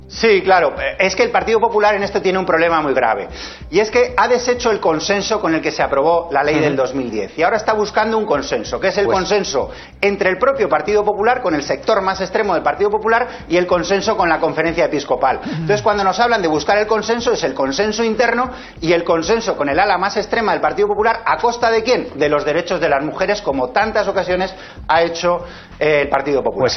Declaraciones de Antonio Hernando Vera en Las mañanas de Cuatro TV un día antes de que se debate y vote en el Congreso la reforma del aborto del PP